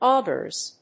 /ˈɔgɝz(米国英語), ˈɔ:gɜ:z(英国英語)/
フリガナオガーズ